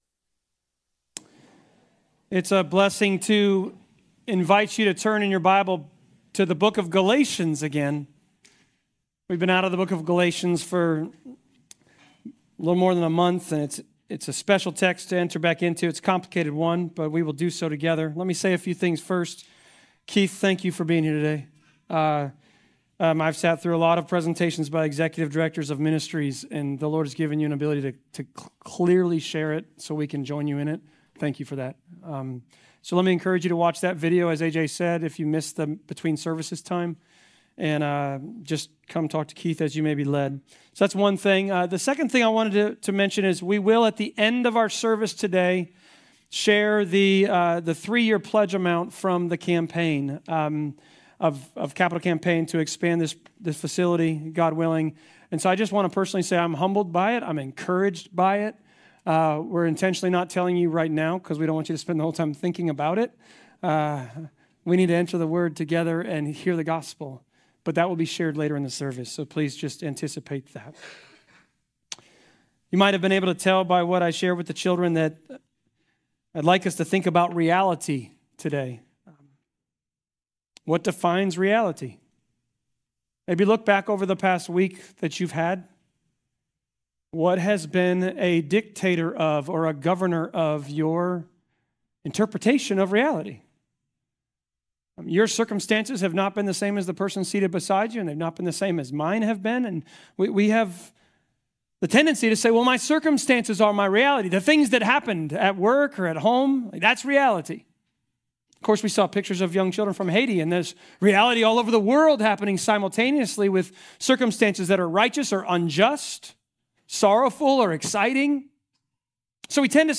Galatians Passage: Galatians 3.15-29 Service Type: Sermons « Lord over life and death